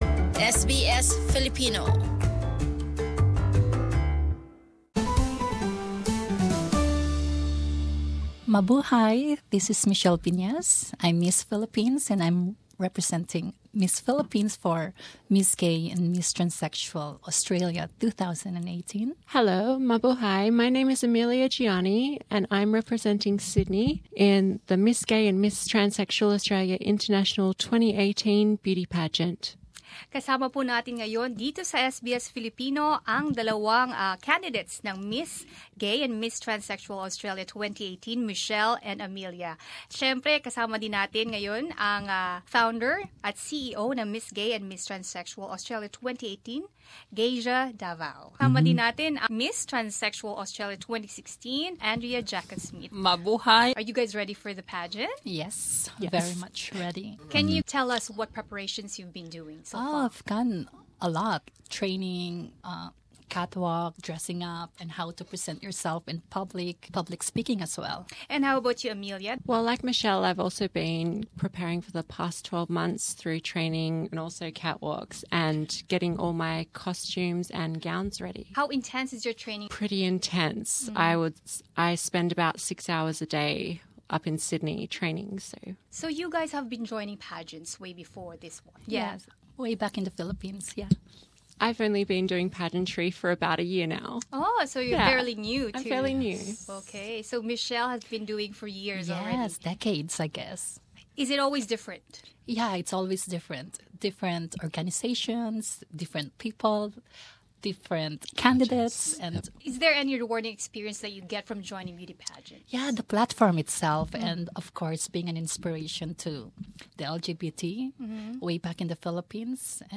Nagbahagi ang mga kandidata at organiser ng Miss Gay at Miss Transsexual Australia 2018 tungkol sa usaping LGBTQIA, mga hamon na hinaharap, tungkol sa napapalapit na internasyunal na pageant na gaganapin sa St. Kilda kasabay ng pride march at kung paano sila nananatiling positibo sa pagkalat ng mensahe ng pagmamahal at pagtanggap.